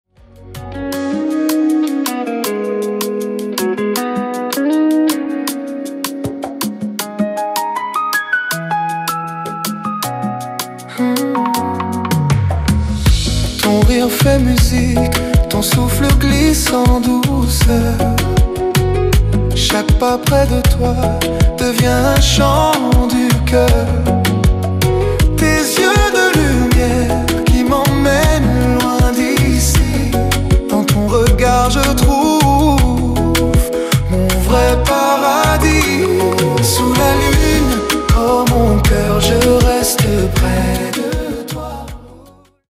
Kizomba kompa
Romantic Sensual
Zouk